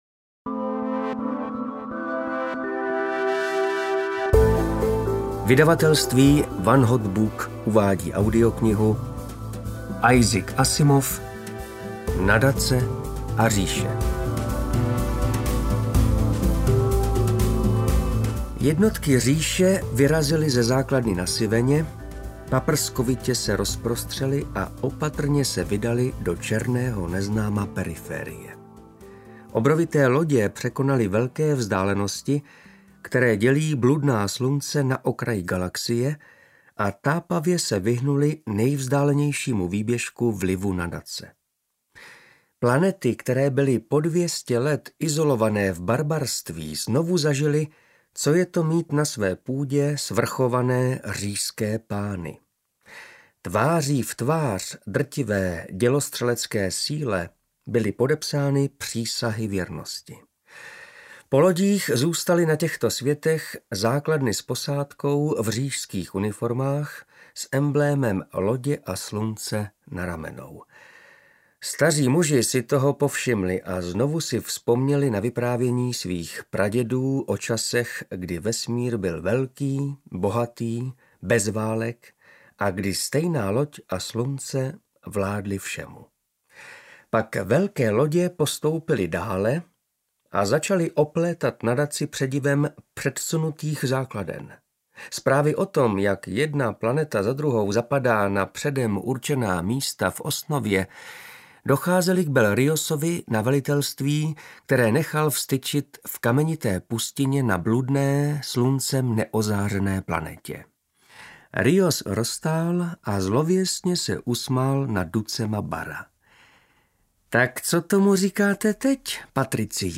Nadace a Říše audiokniha
Ukázka z knihy